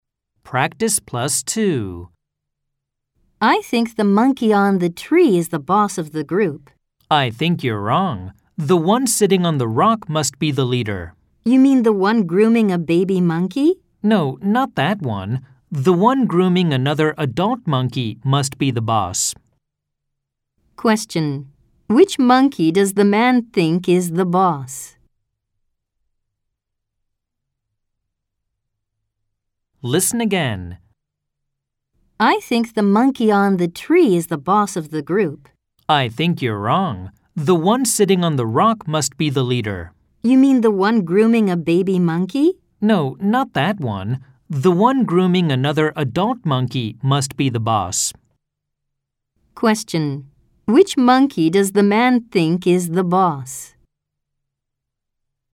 計7名以上（米・英・豪）によるバラエティ豊かな音声を収録。
2回読み